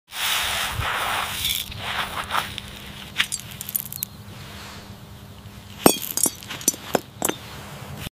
ASMR glass garden vegetables, potato